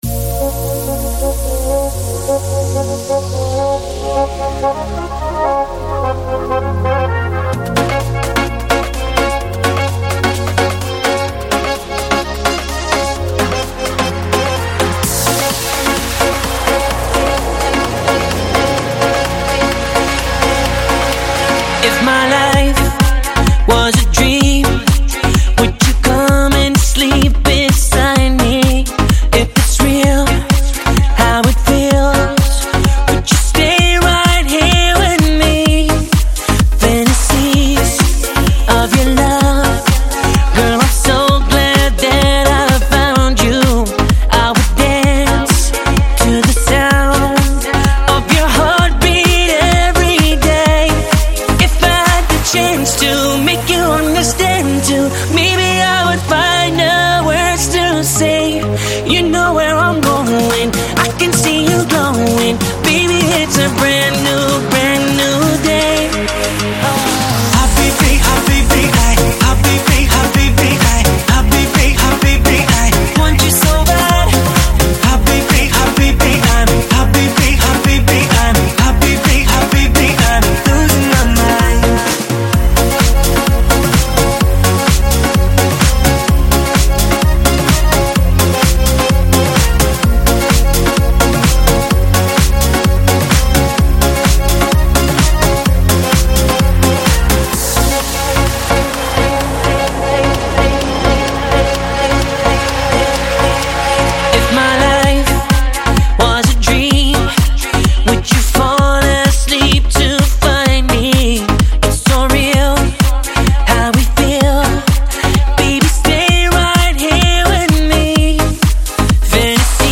Ritmo cool